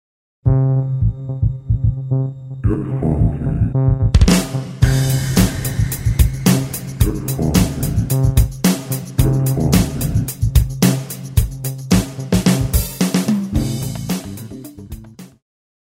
爵士
套鼓(架子鼓)
乐团
演奏曲
独奏与伴奏
有主奏
有节拍器